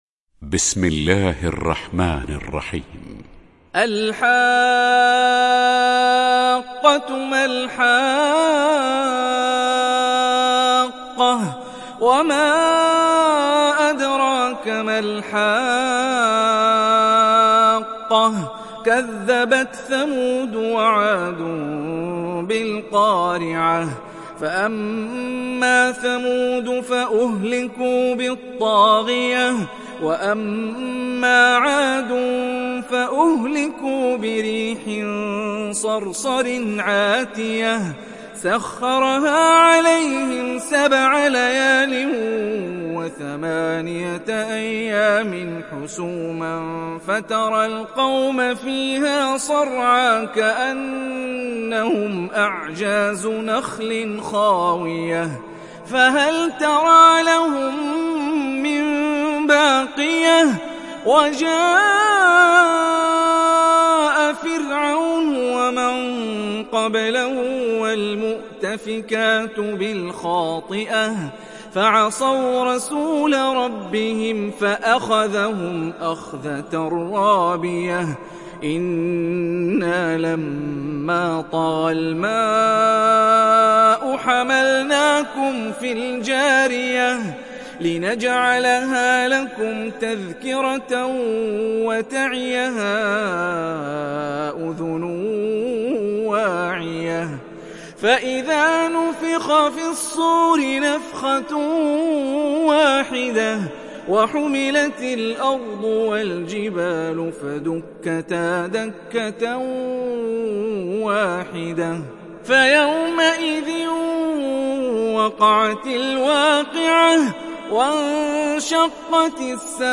دانلود سوره الحاقه mp3 هاني الرفاعي روایت حفص از عاصم, قرآن را دانلود کنید و گوش کن mp3 ، لینک مستقیم کامل